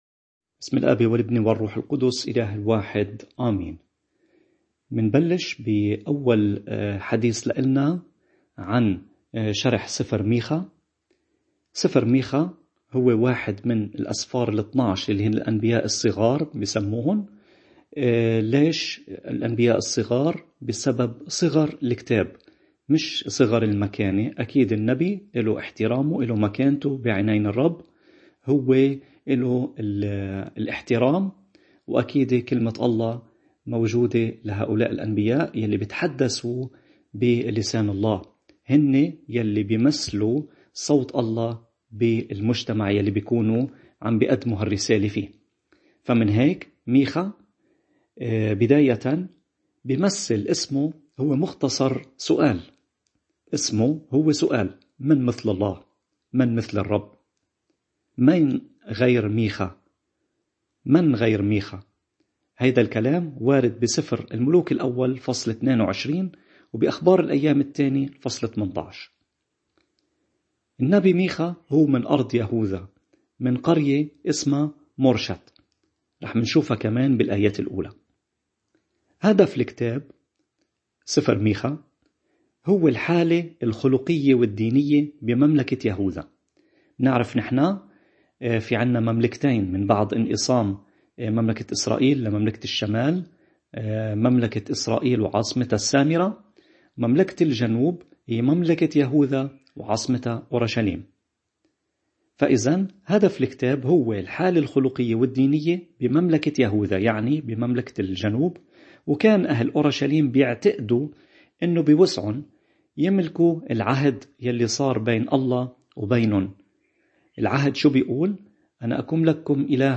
نور ونار لشرح الإنجيل المقدّس، عظات، مواضيع وأحاديث روحيّة، عقائديّة ورهبانيّة…